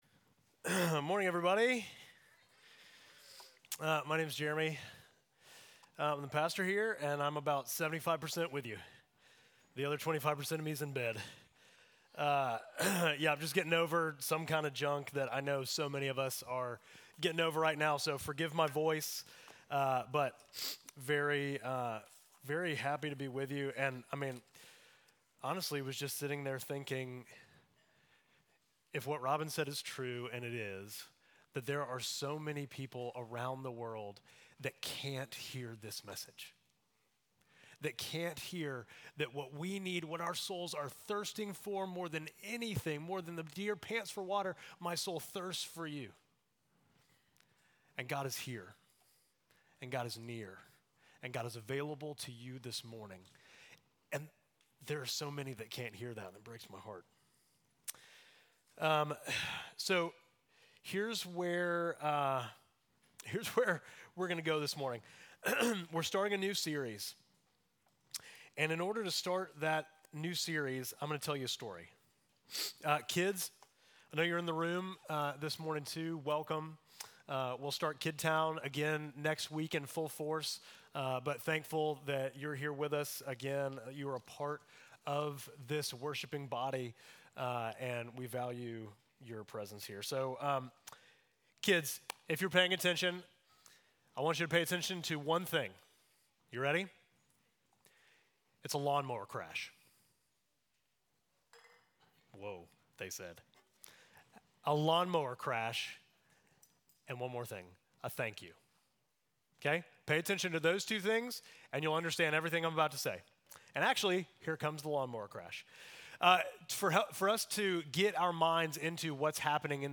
Midtown Fellowship Crieve Hall Sermons Grace to You and Peace Jan 26 2025 | 00:35:10 1x 00:00 / 00:35:10 Subscribe Share Apple Podcasts Spotify Overcast RSS Feed Share Link Embed